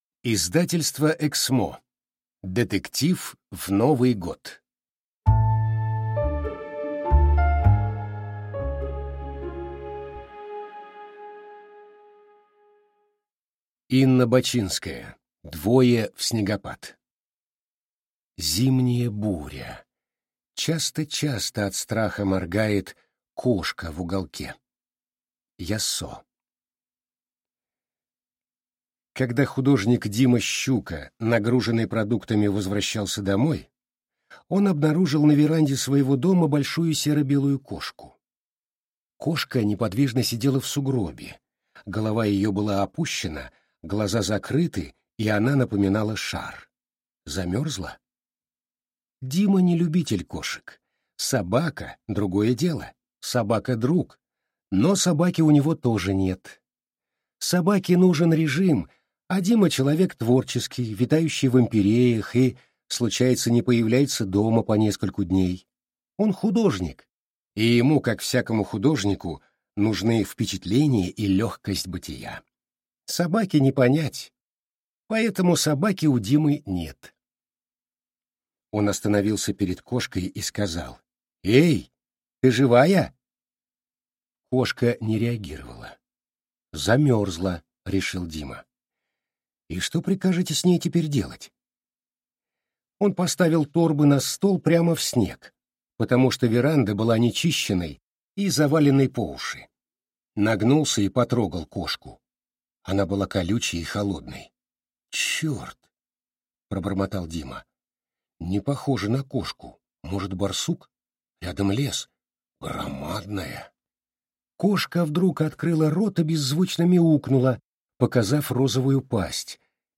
Аудиокнига Детектив в Новый год | Библиотека аудиокниг